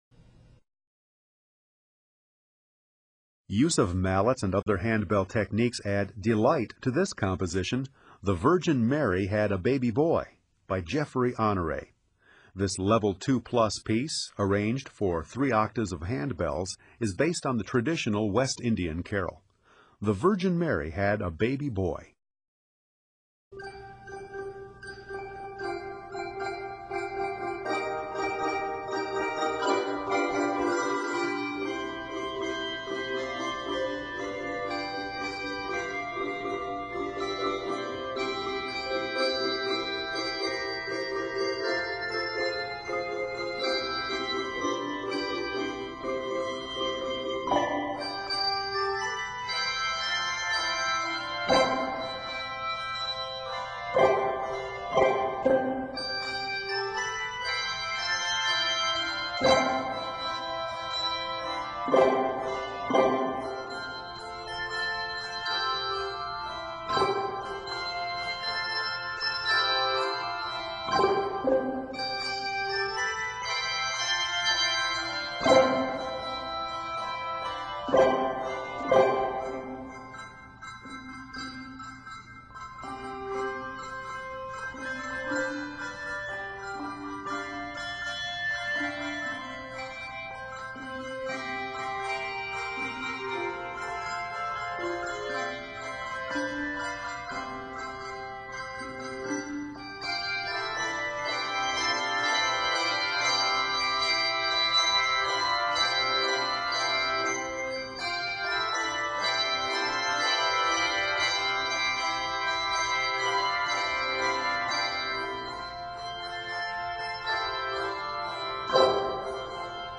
Octaves: 3